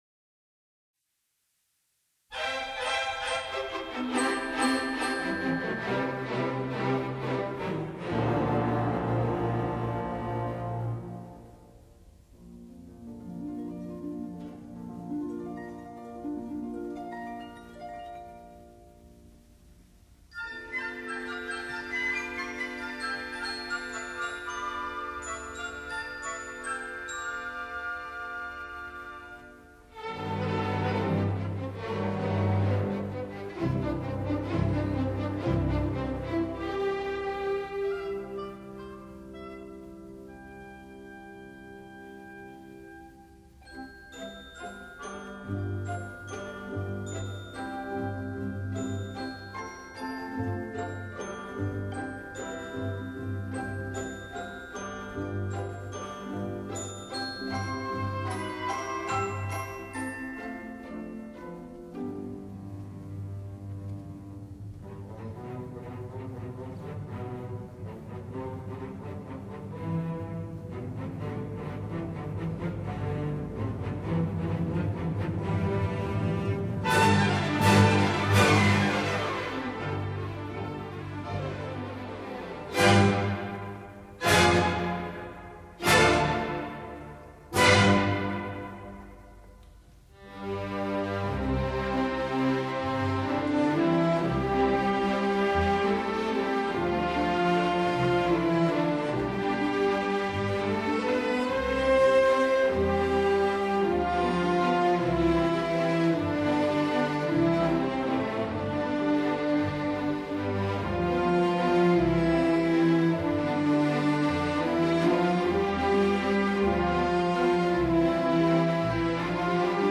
第二圆舞曲转到F大调上，它的(B)段在序奏和结尾里都出现。
主题反复时音量减弱，由木管的重奏造成柔和的气氛。
结尾从c小调开始，再现了第一、第二圆舞曲后在活跃的气氛中终曲。